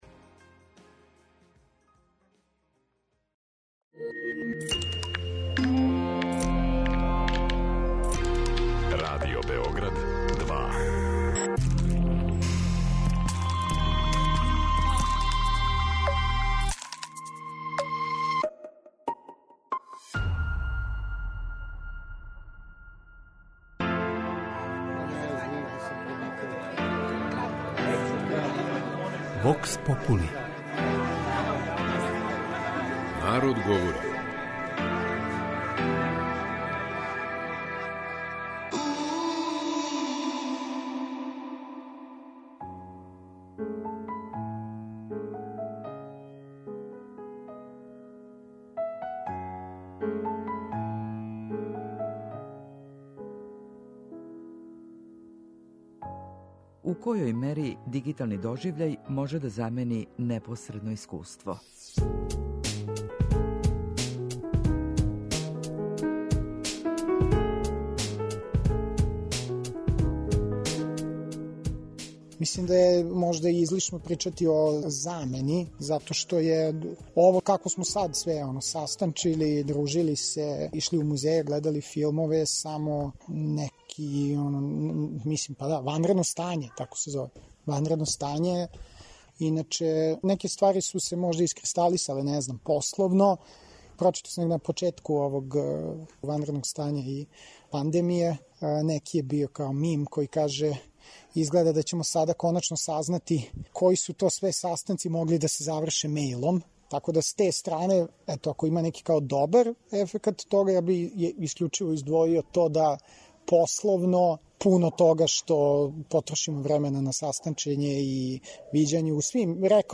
кроз кратке монологе, анкете и говорне сегменте у којима ће случајно одабрани, занимљиви саговорници одговарати на питања